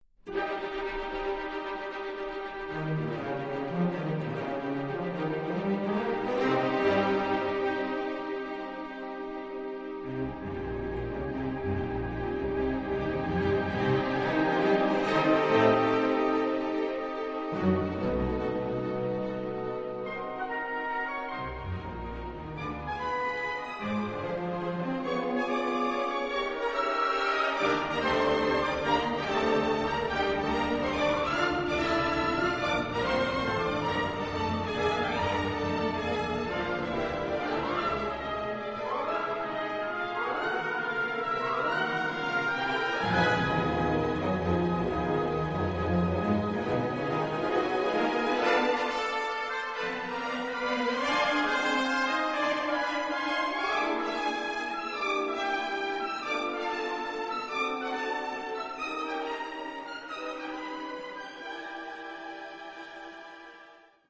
8) Symphonic poem Op. 32 14:42